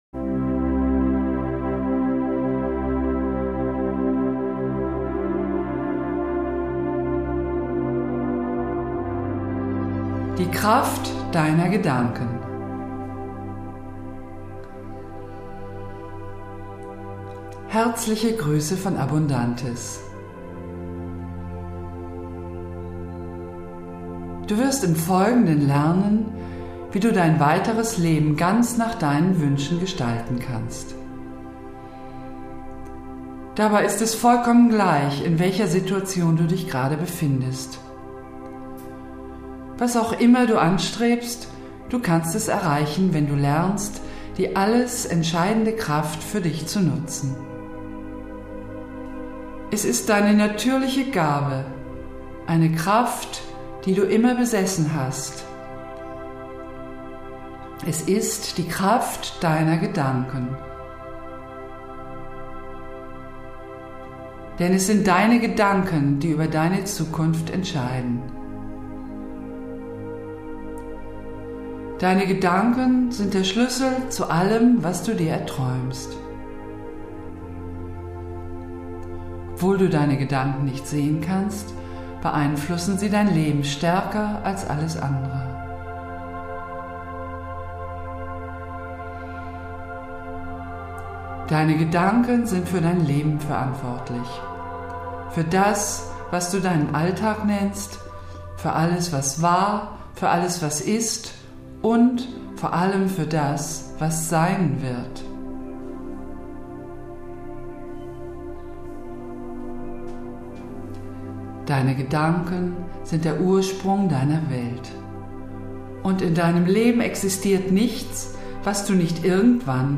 Harmonische, sanft fließende Instrumentalmelodien tragen zur Entspannung bei